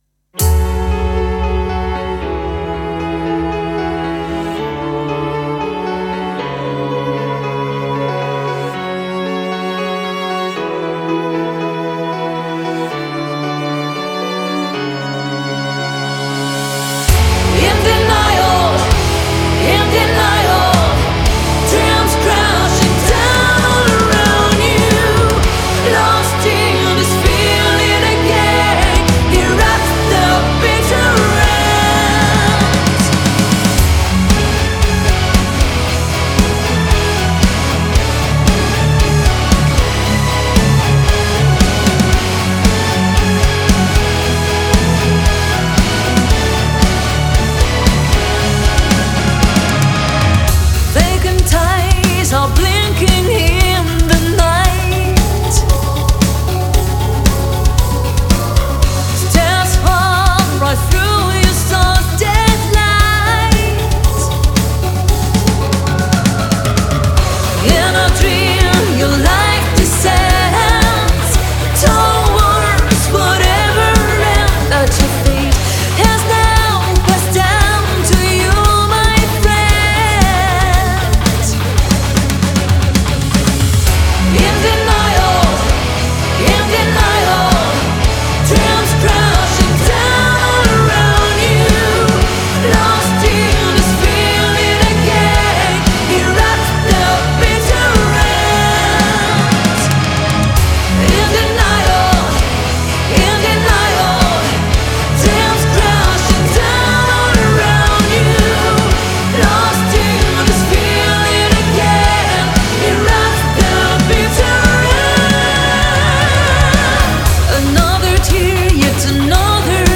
Genre: Symphonic metal